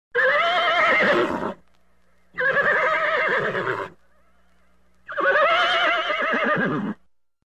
Horse Neigh Growler
horse.mp3